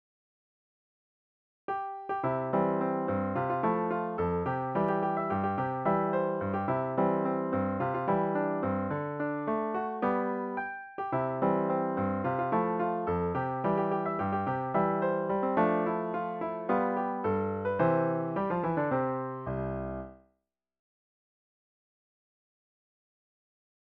Vocal solo and piano